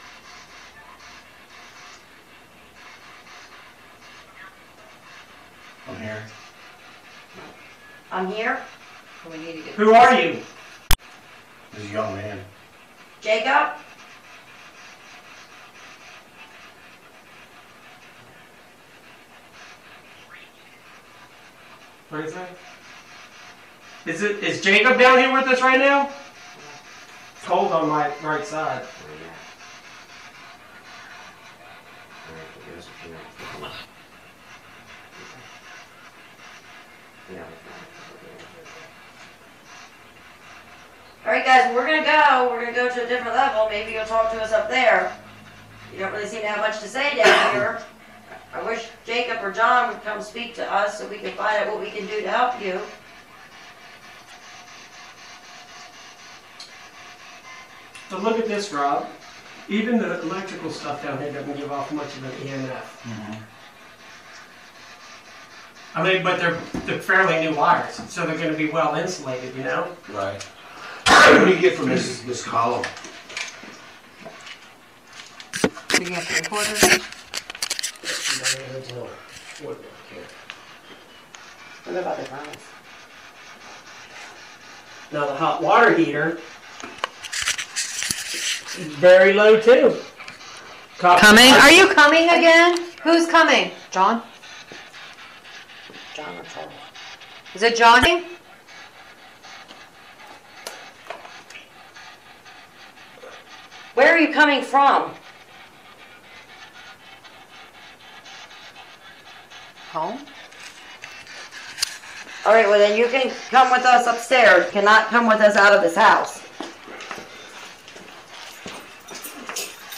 when we were in kitchen